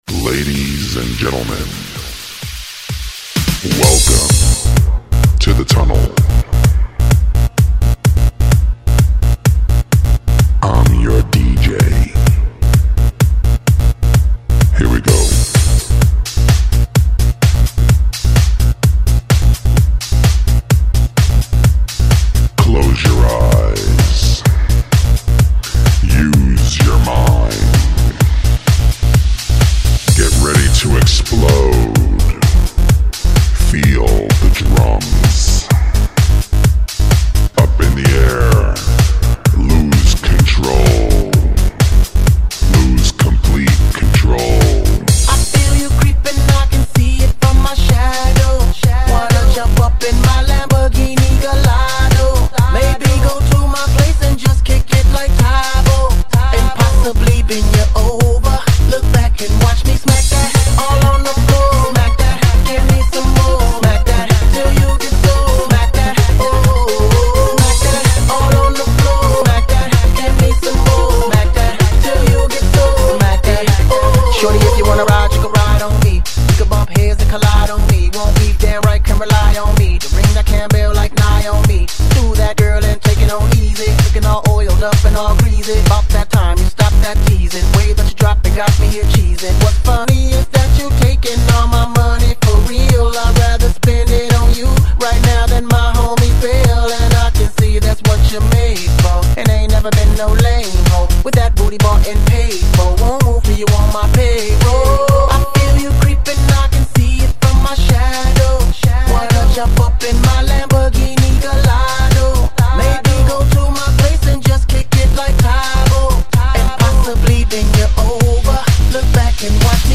*** стиль (electro house) ***